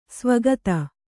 ♪ svagata